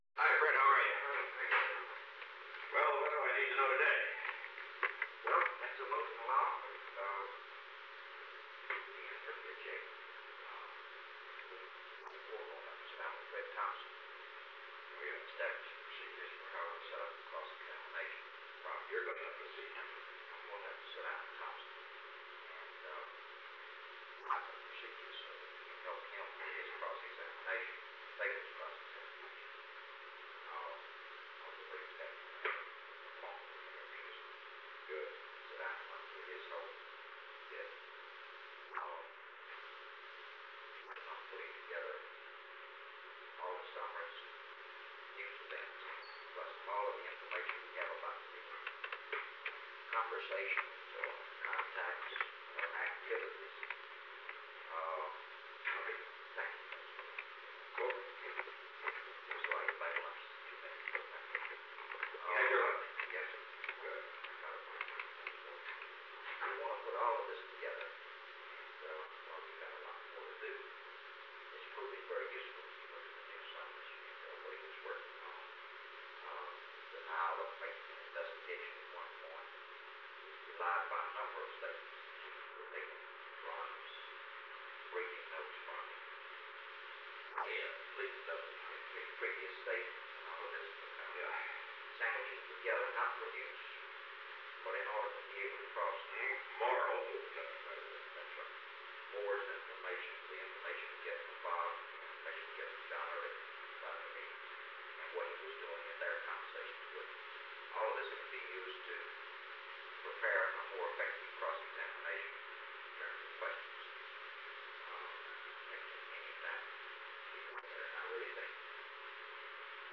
Conversation No. 444-5 Date: June 11, 1973 Time: Unknown between 2:13 pm and 2:55 pm Location: Executive Office Building The President met with J. Fred Buzhardt. Watergate -John W. Dean, III
Secret White House Tapes | Richard M. Nixon Presidency 444–5